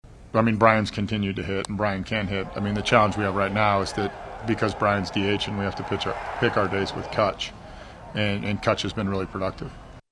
Shelton says it’s been difficult to get two of his best bats into the lineup since the start of the season.